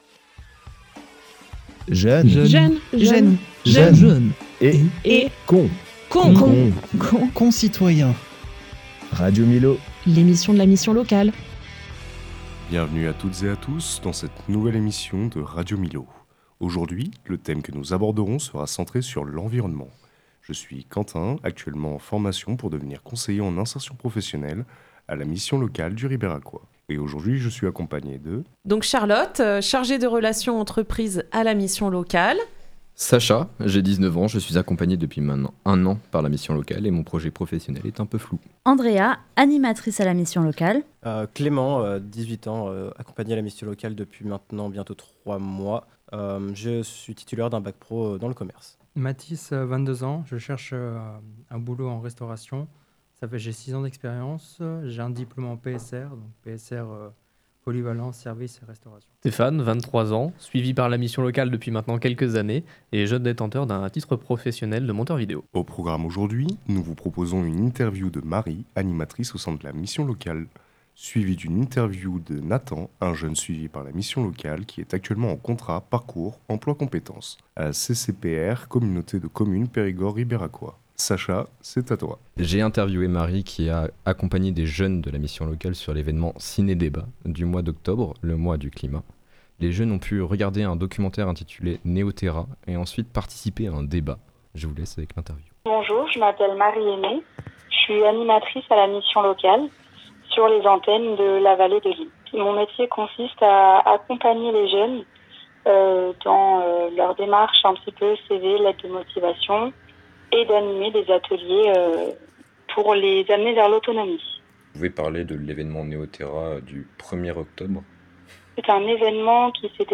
La mission locale et des jeunes prennent le micro.